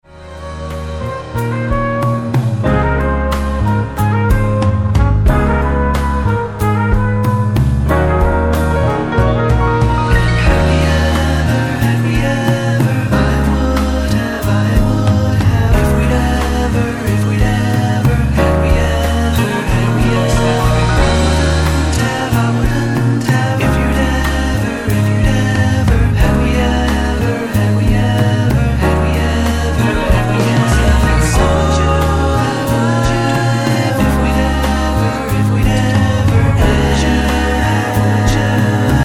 SOFT ROCK/GUITAR POP
ソフトロック、ボサにギターポップまで、全てを取り込み鳴らされる魅惑のポップサウンド。